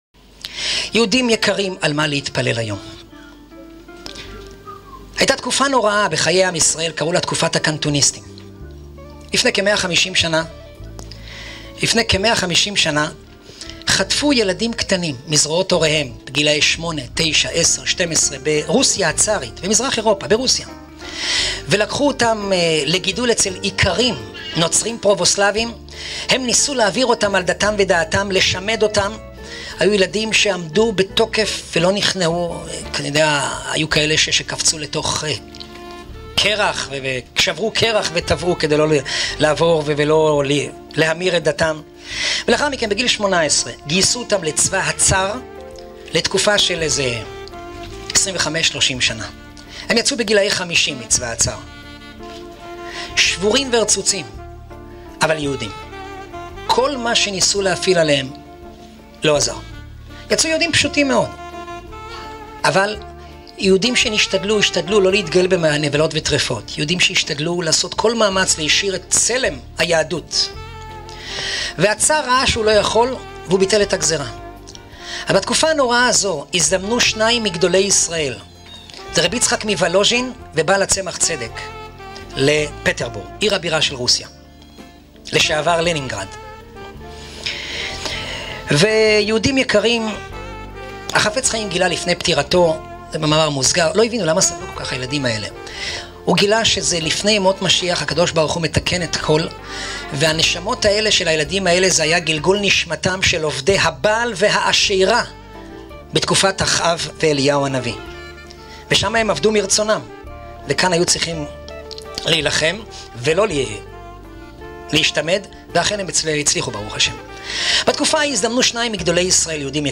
במערכת חסדי נעמי שעורי תורה מפי רבנים שונים